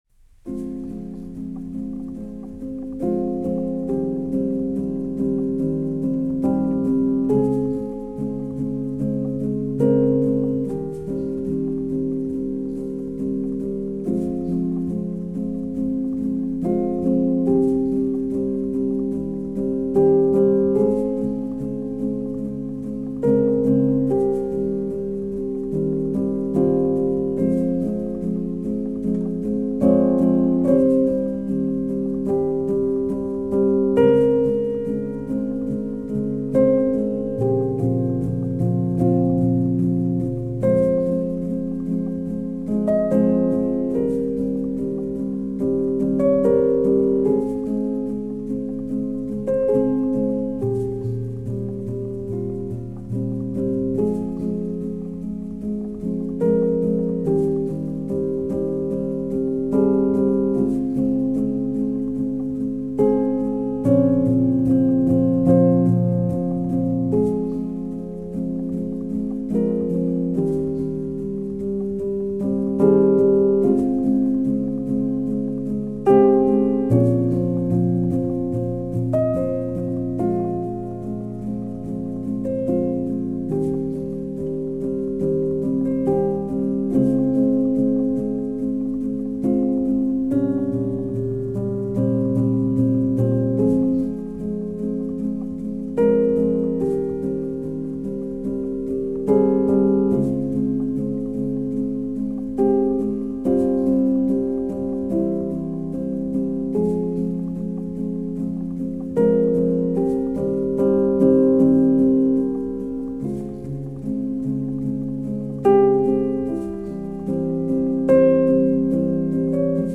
a modern classical / classical crossover solo piano single